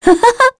Scarlet-vox-Happy1.wav